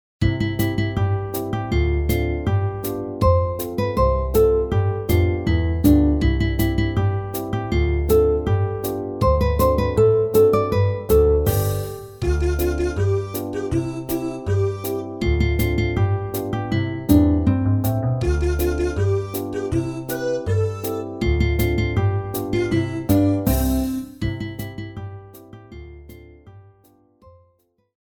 RÉPERTOIRE  ENFANTS